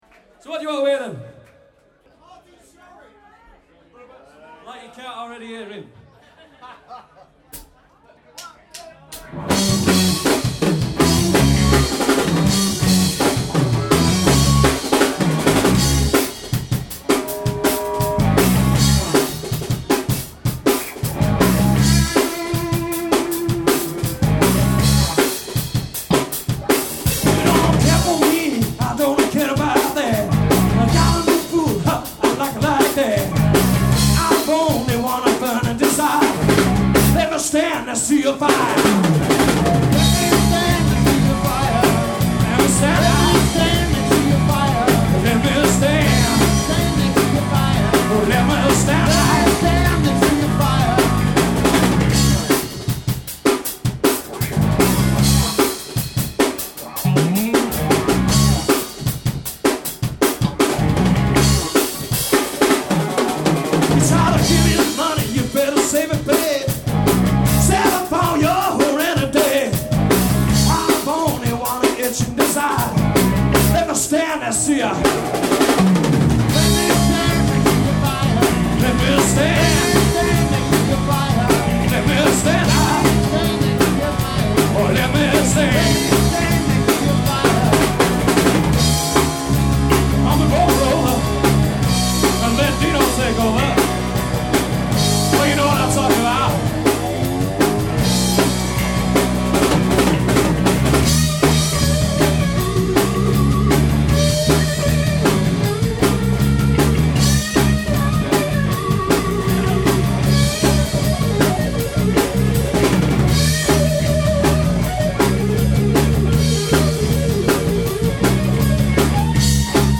a blues/rock/soul power trio fronted by
guitar & vocals
bass and vocals
drums and vocals